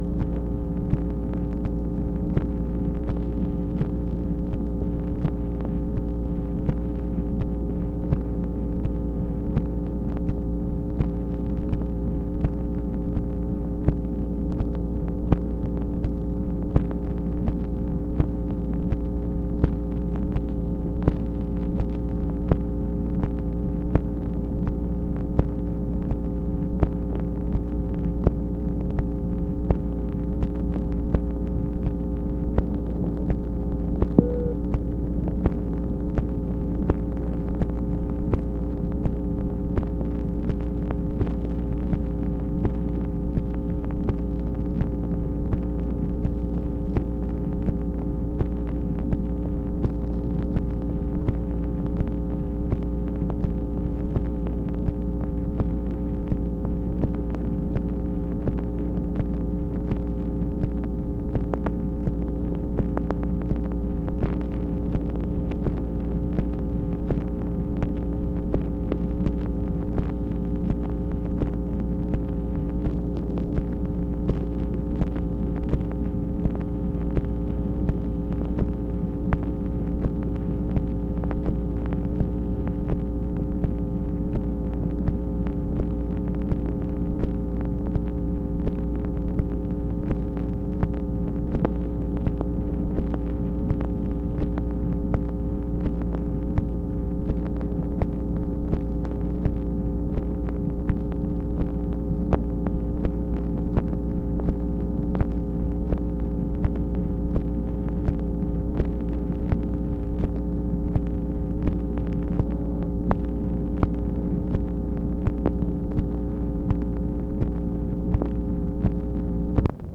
MACHINE NOISE, January 3, 1967
Secret White House Tapes | Lyndon B. Johnson Presidency